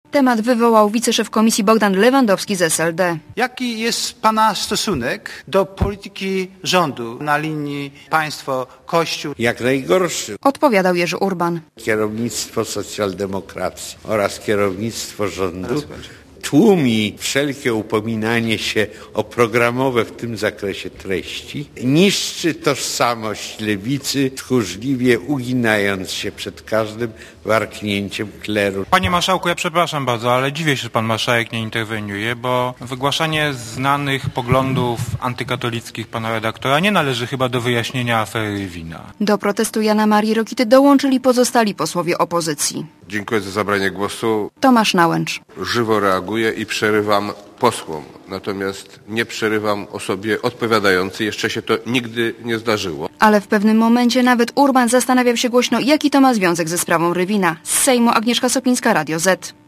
Posłuchaj reportera Radia Zet i Jerzego Urbana (466 KB)